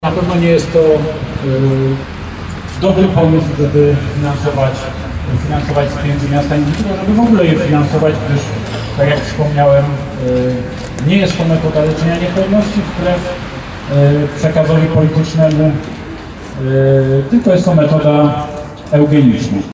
Bielsko-Biała: kilkadziesiąt osób przyszło przed Ratusz odmawiać różaniec. Chodzi o decyzję dot. ewentualnego dofinansowania zabiegów in vitro z kasy gminy.
Metoda in vitro jest metodą niedopuszczalną – mówił jeden z organizatorów wydarzenia.